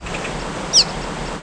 Cave Swallow Petrochelidon pelodoma
Flight call description A descending call (a pure-toned "psuer" or a more husky "nhew") and a soft, rising call ("swheet" or "nhwit").
Descending ("nhew") call from bird of West Indian population (P. p. fulva group) in flight.